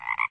th_sfx_animal_frog.ogg